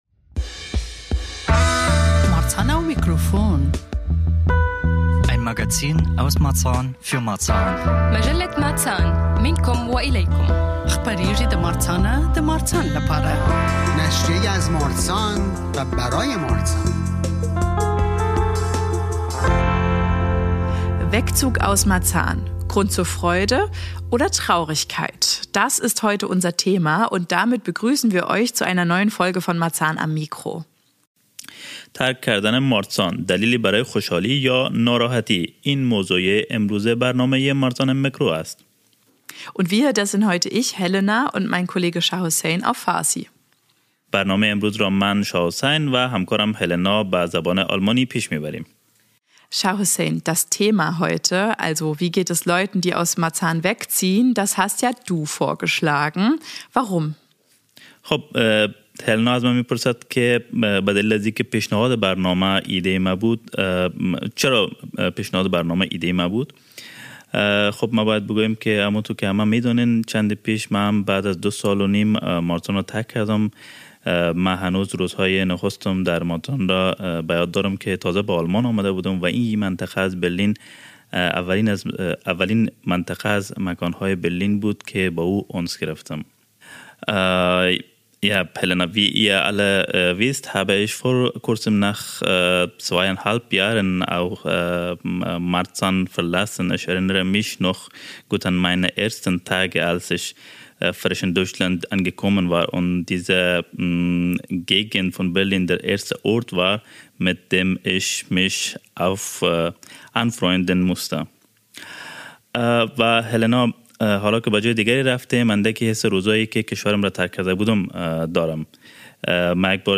Darin erzählen Menschen, die einst in Marzahn lebten und heute anderswo wohnen, von ihren Erfahrungen mit dem Wegzug aus dem Bezirk.